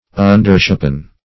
Search Result for " undershapen" : The Collaborative International Dictionary of English v.0.48: Undershapen \Un"der*shap`en\, a. Under the usual shape or size; small; dwarfish.